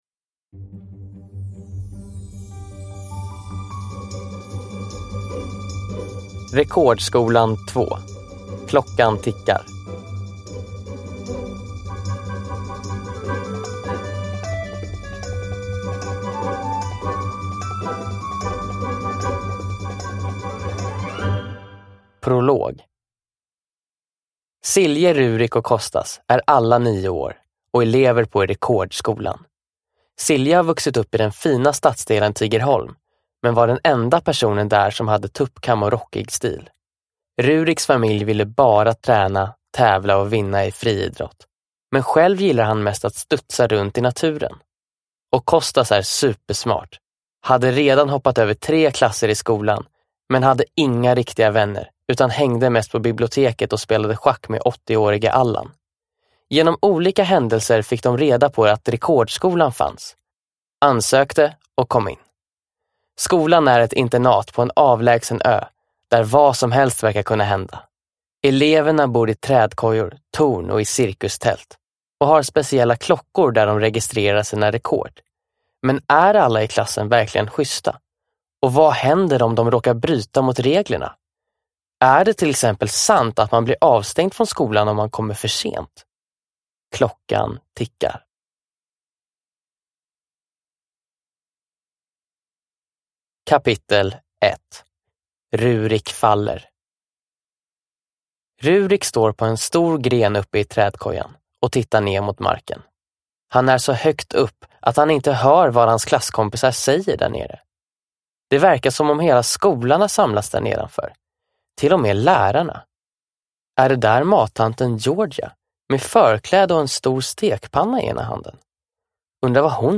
Rekordskolan. Klockan tickar – Ljudbok – Laddas ner